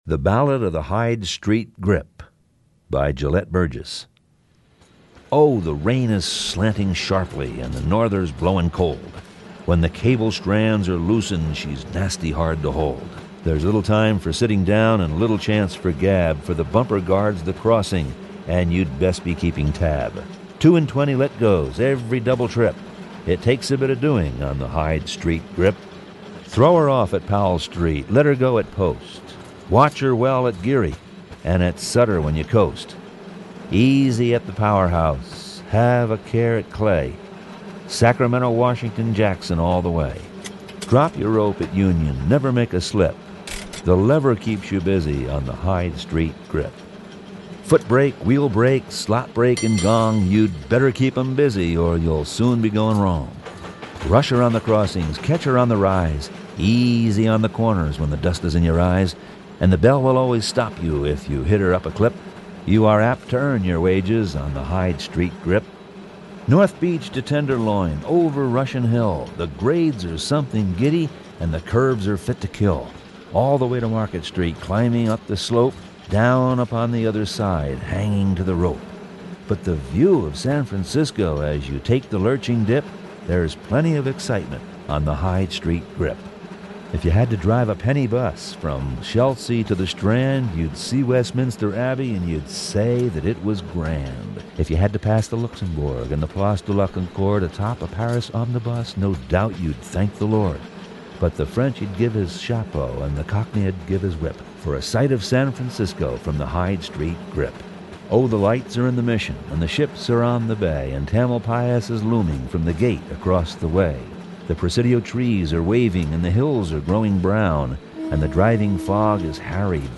The poem describes the line before the 1906 Earthquake and Fire. This reading was recorded especially for this site to commemorate the 50th anniversary of the abandonment of the once important O'Farrell, Jones and Hyde line.
hyde_street_grip.mp3